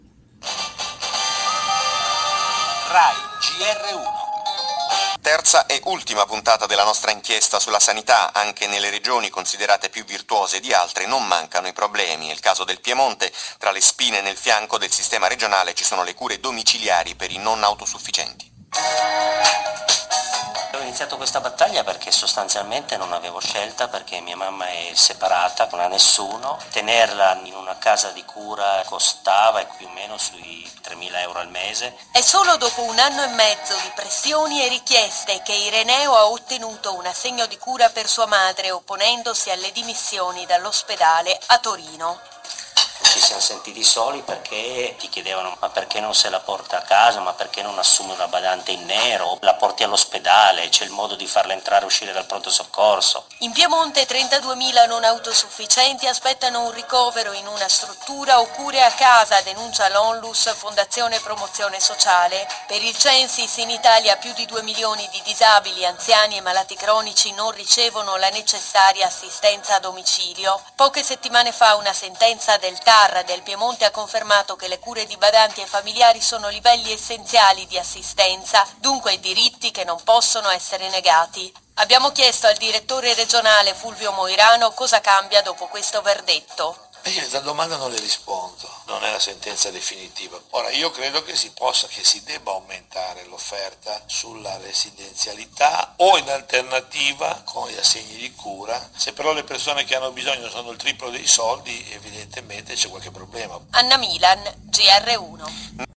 In questo contesto allarmano le parole del Direttore generale della sanità piemontese, Fulvio Moirano, che durante il
Giornale radio Gr1 delle ore 8,00 del 23 aprile 2015 ha risposto a precisa domanda sull’erogazione delle cure domiciliari dopo le sentenze del Tar del Piemonte che le riconoscevano come diritti esigibili: «E io a questa domanda non le rispondo.